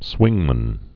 (swĭngmən)